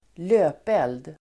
Ladda ner uttalet
Uttal: [²l'ö:pel:d]